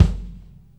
rolli ring kick.wav